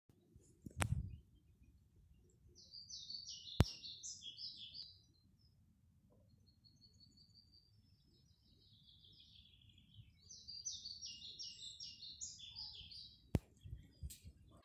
Birds -> Flycatchers ->
Pied Flycatcher, Ficedula hypoleuca
StatusSinging male in breeding season